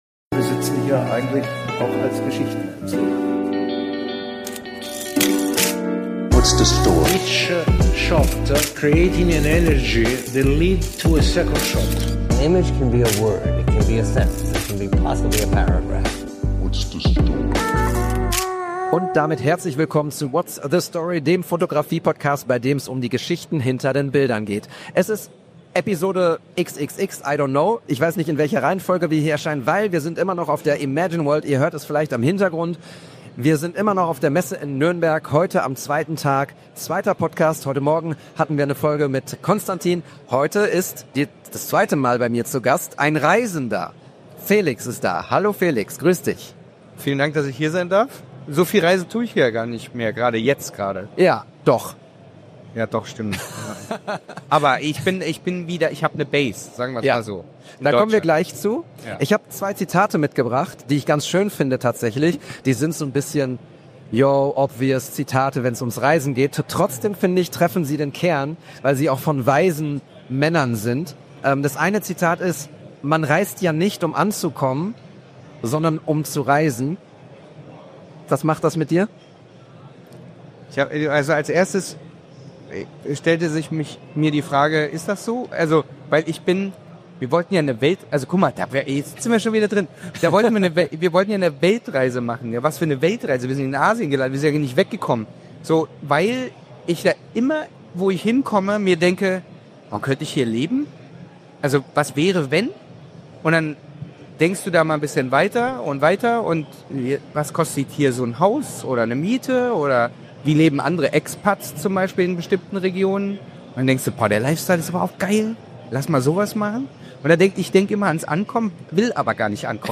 Live von der Imagine World